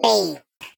Sfx_tool_spypenguin_vo_horn_06.ogg